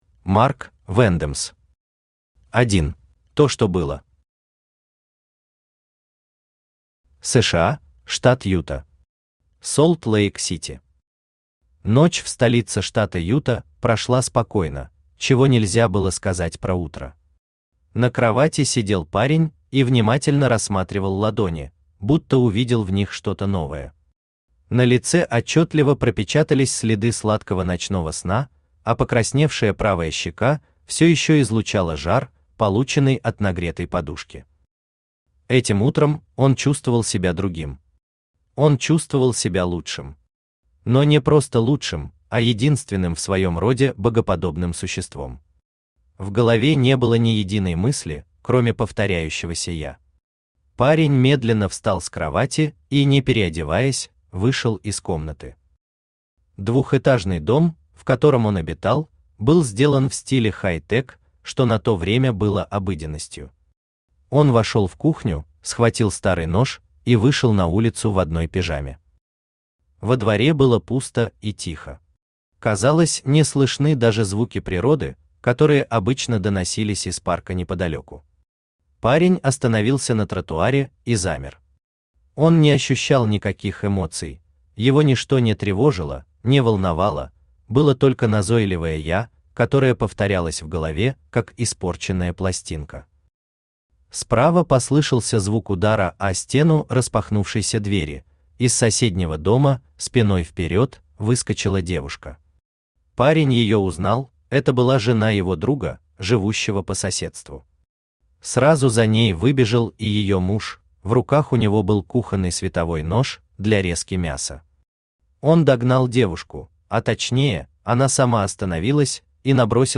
Аудиокнига один | Библиотека аудиокниг
Aудиокнига один Автор Марк Вэндэмс Читает аудиокнигу Авточтец ЛитРес.